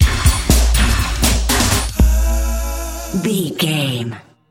Ionian/Major
synthesiser
drum machine
electric guitar
drums
strings
Eurodance